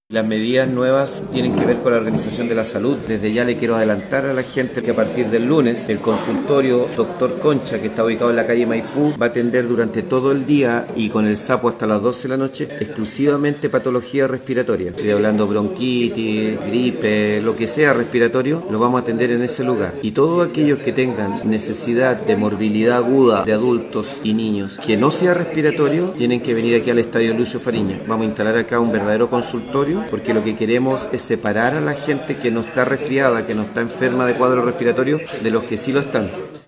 Pero además se destinará el Estadio Municipal “Lucio Fariña” como un centro de atención para patologías no respiratorias, para niños y adultos, tal como lo explicó el alcalde de Quillota, doctor Luis Mella Gajardo.
01-ALCALDE-Atención-en-Consultorio-y-Estadio.mp3